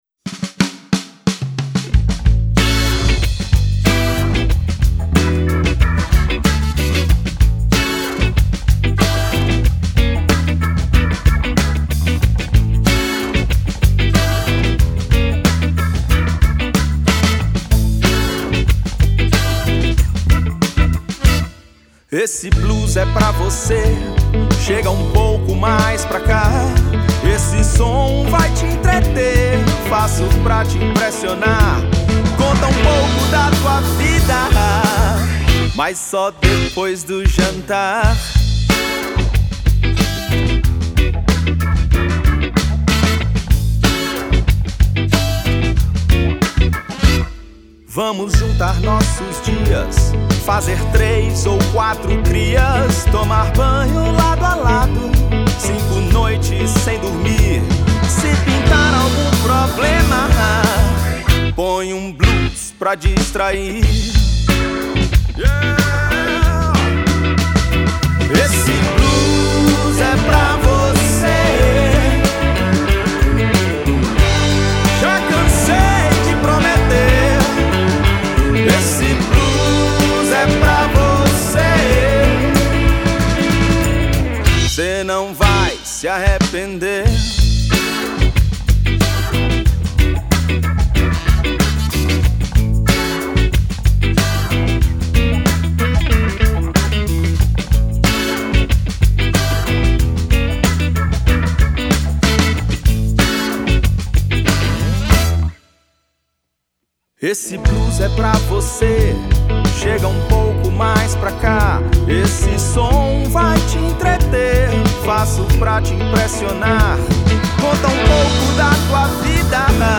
2259   04:42:00   Faixa:     Jazz